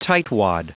Prononciation du mot tightwad en anglais (fichier audio)
tightwad.wav